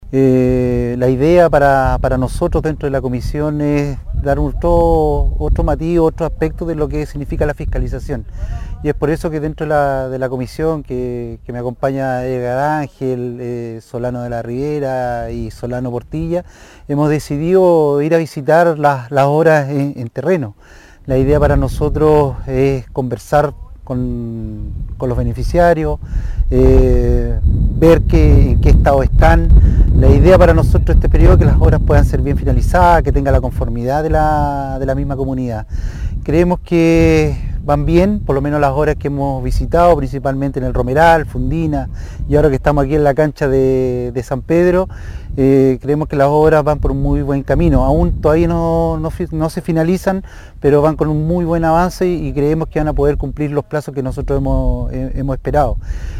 Mientras que el presidente de la comisión de Infraestructura del Concejo Municipal, el concejal Rodrigo Hernández, comentó el objetivo de la visita en terreno, destacando el porcentaje de avances de las obras que concretan en la comuna.